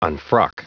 Prononciation du mot unfrock en anglais (fichier audio)
Prononciation du mot : unfrock